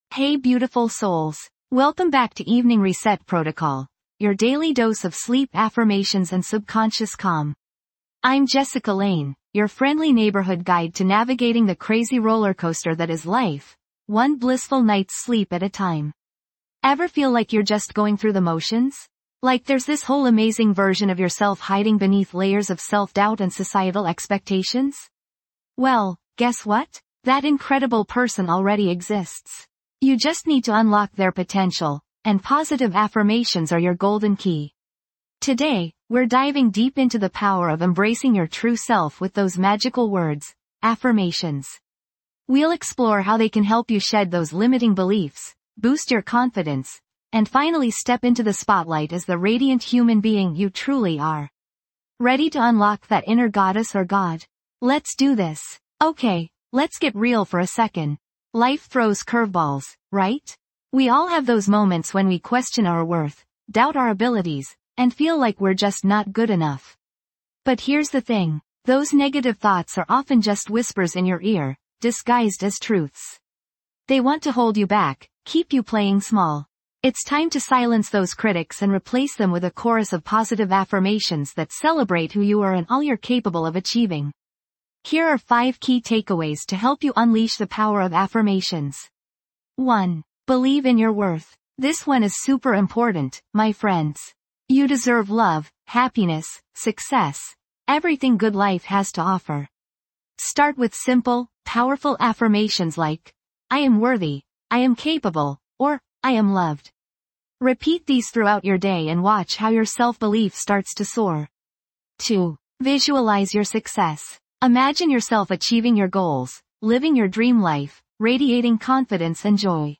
Through soothing soundscapes and carefully crafted affirmations, we'll help you release the day's burdens, rewire negative thought patterns, and cultivate a sense of inner peace.